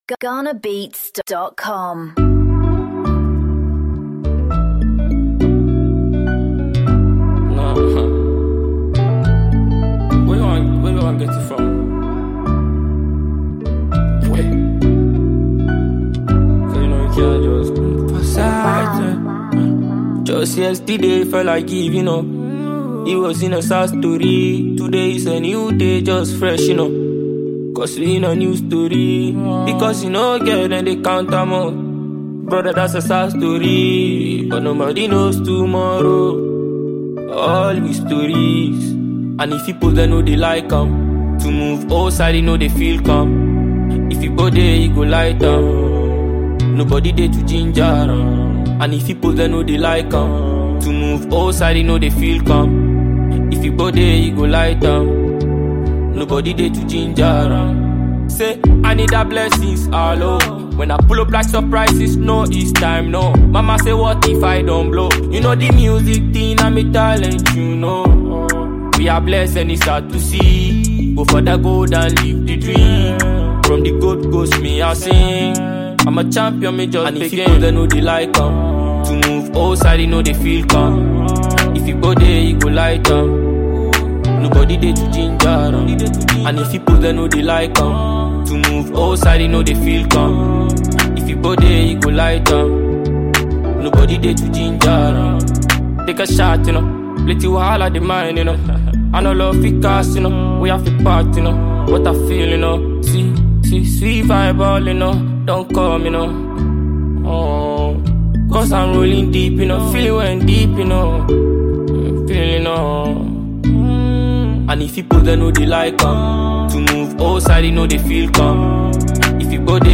a powerful fusion of Afro-Dancehall and Afrobeats
raw vocals, Afro-dancehall rhythms, and introspective lyrics